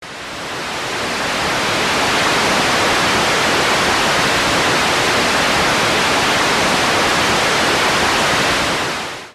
Although the DAC is now only 0 or 128 (like tossing a coin), at a clockfrequency above audio the result sounds and measures like white noise in the audio band.
here is a Noise sound example As used in MonoVoks and MonoLadder synths